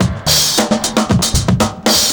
112CYMB11.wav